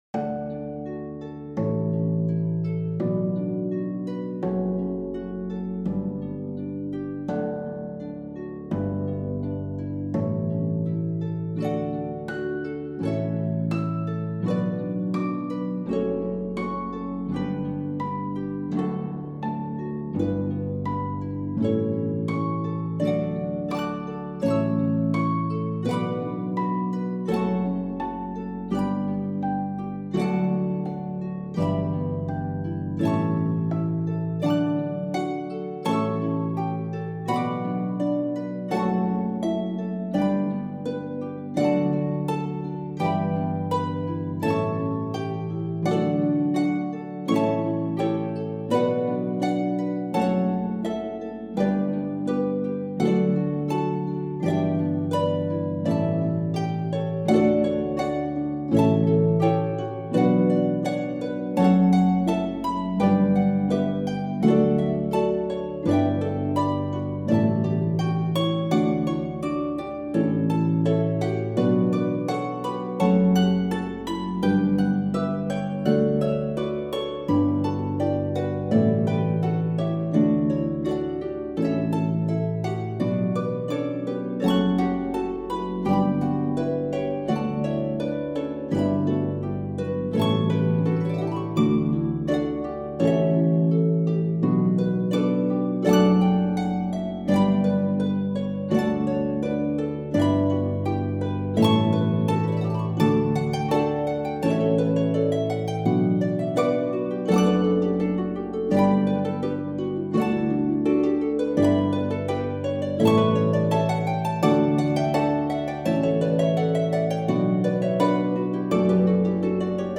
for four pedal harps
Lever changes between C-sharp and C-natural are required.